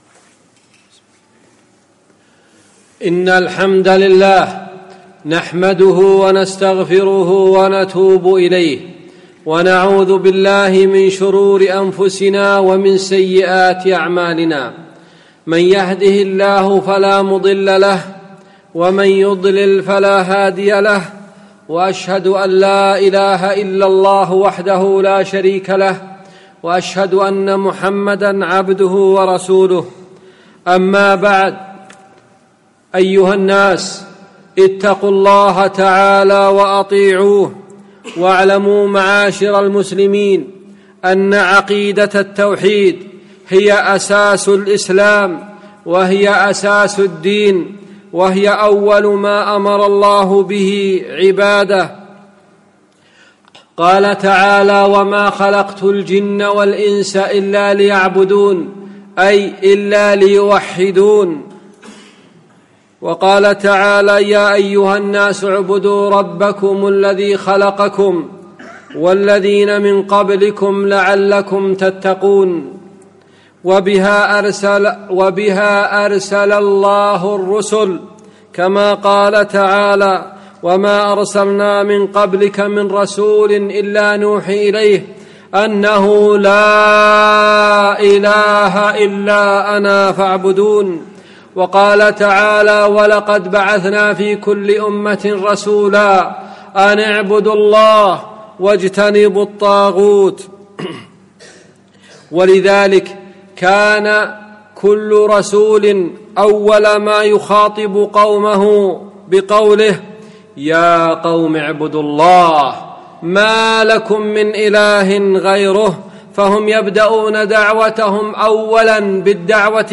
أهمية التوحيد - خطبة